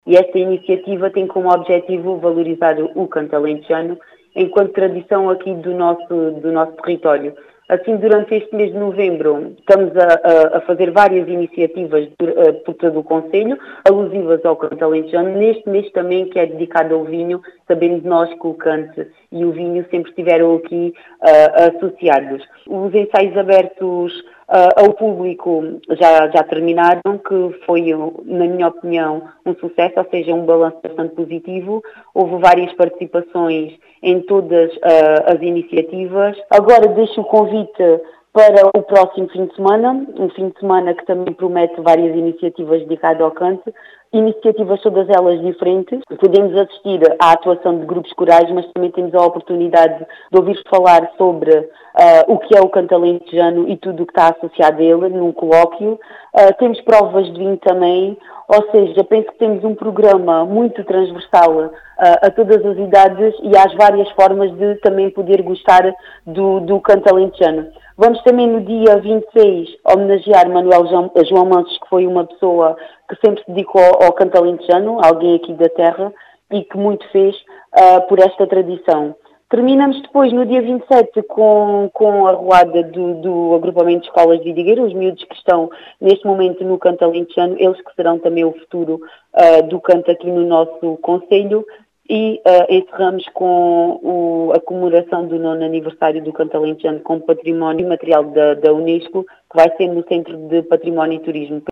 As explicações e os destaques foram deixadas pela presidente da junta de freguesia, Carla Penas.